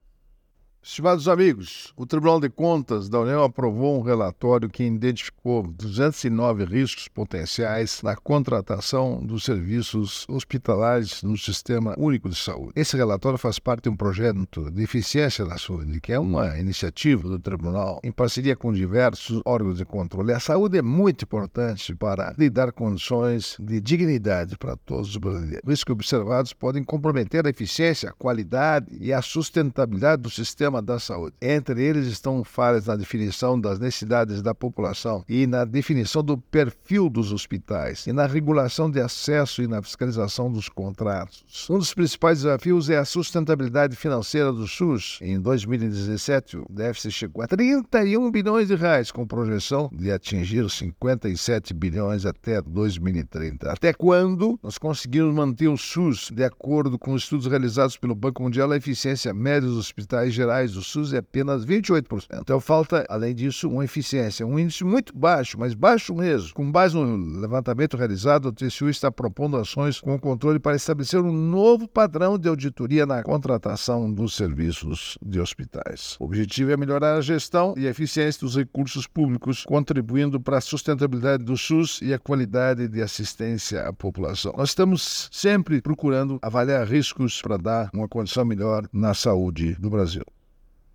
É o assunto do comentário desta terça-feira (20/08/24) do ministro Augusto Nardes (TCU), especialmente para OgazeteitO.